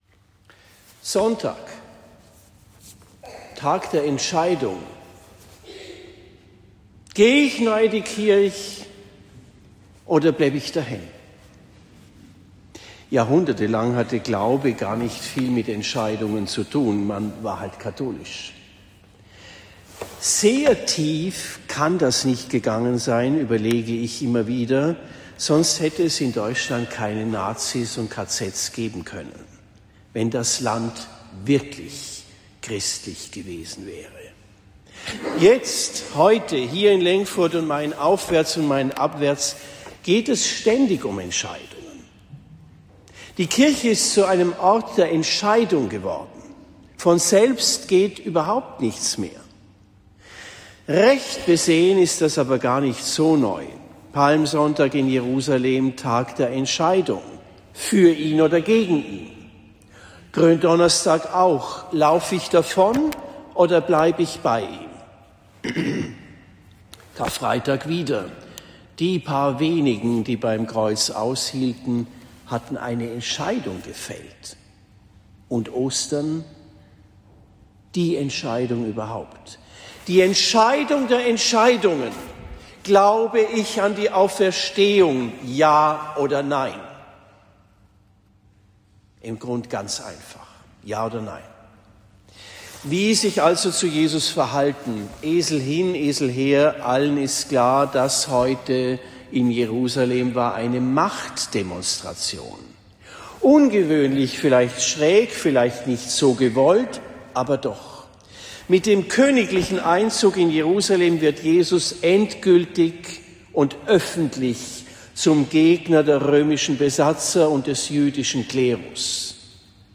Predigt in Lengfurt am 29. März 2026